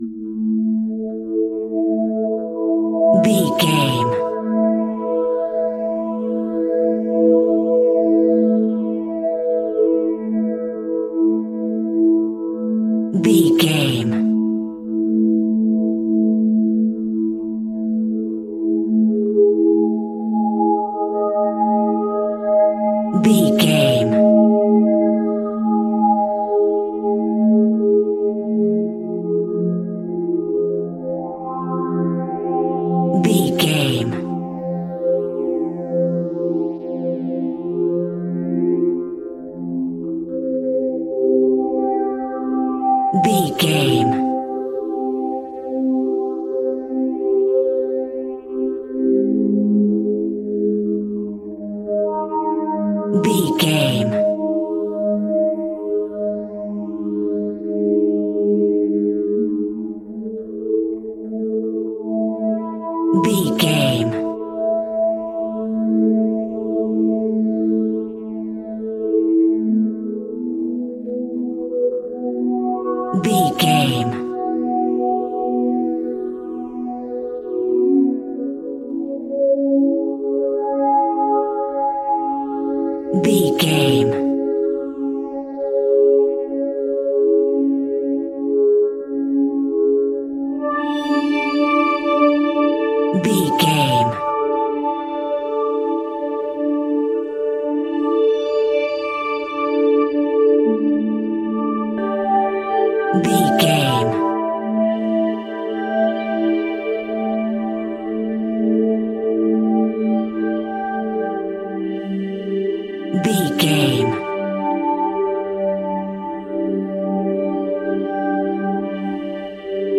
Aeolian/Minor
tension
ominous
dark
eerie
synthesizer
mysterious
horror music
Horror Pads
Horror Synths